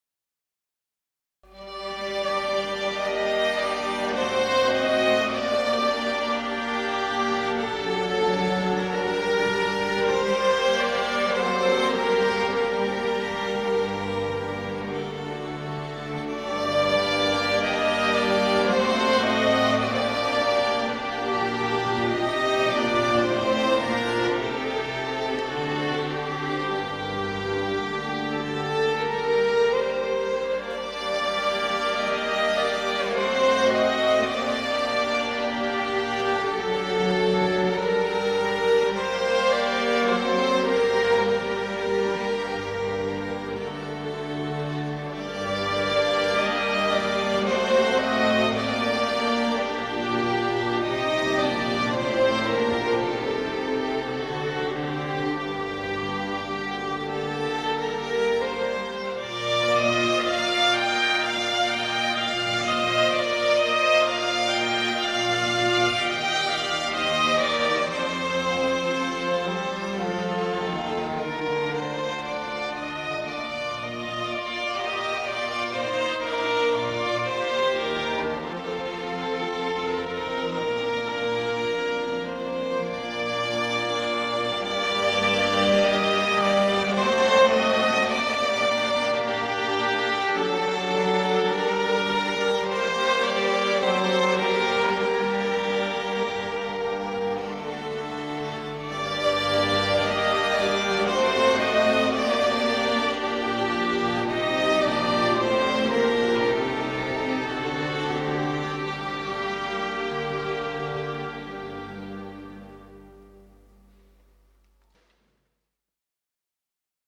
02_02_sarabanda.mp3